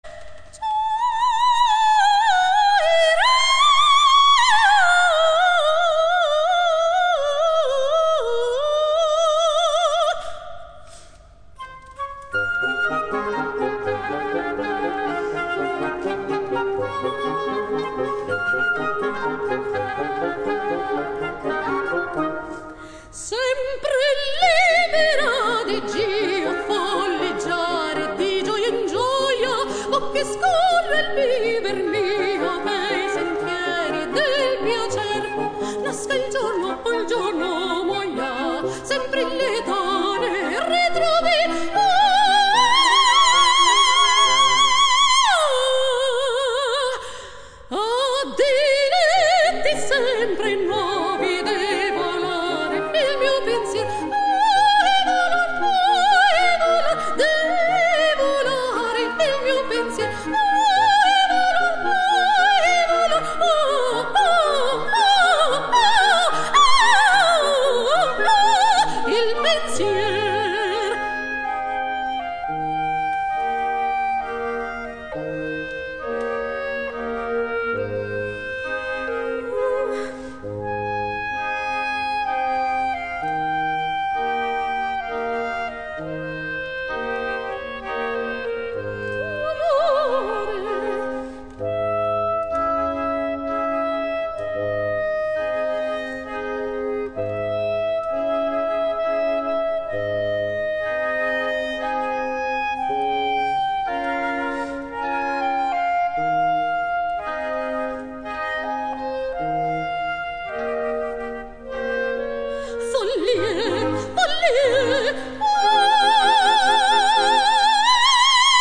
Registrazione live effettuata il 3 Gennaio 2008
nella Chiesa Regina Coeli – AIROLA (BN)